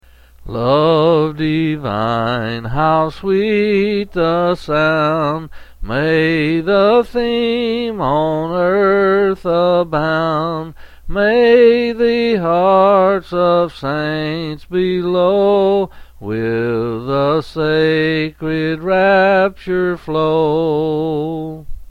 Quill Selected Hymn
7s